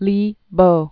(lē bō)